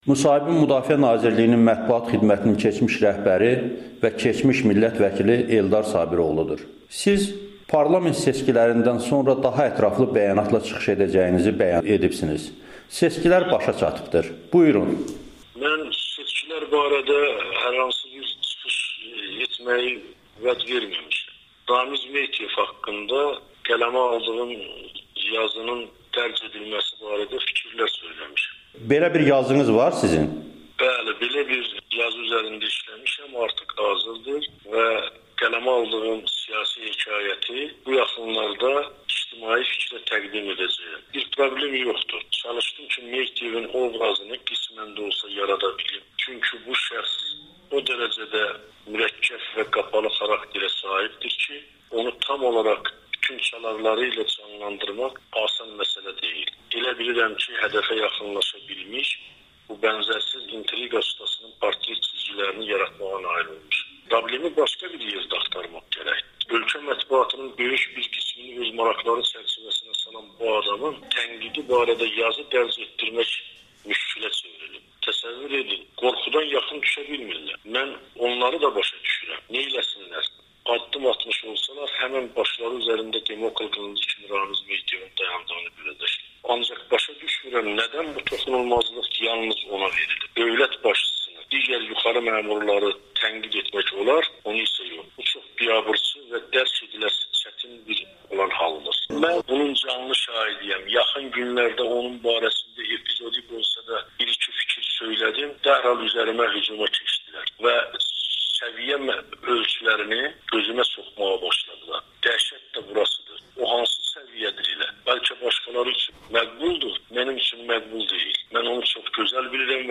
Eldar Sabiroğlu: Ramiz Mehdiyev barəsində siyasi hekayət yazmışam [Audio-müsahibə]
Eldar Sabiroğlunun Amerikanın Səsinə müsahibəsi